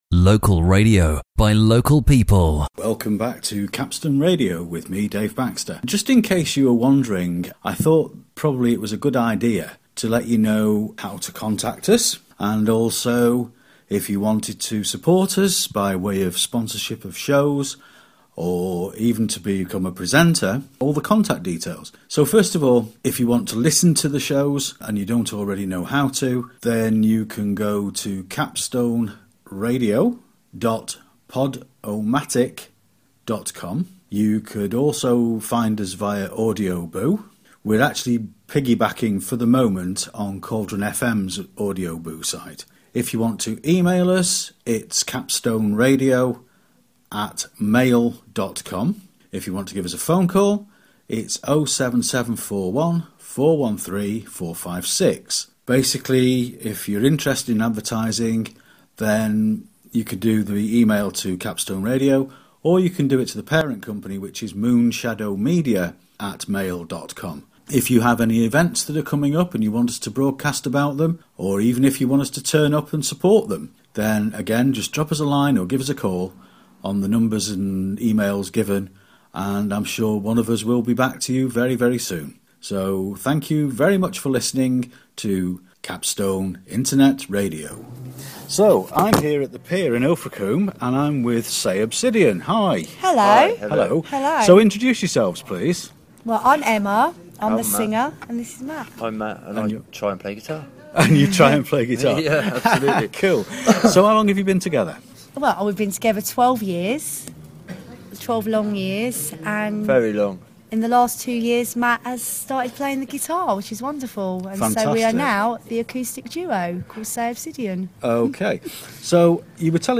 This broadcast contains the contact information for Capstone Radio and also an interview with local acoustic duo "Say Obsidian"